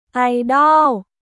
アイドン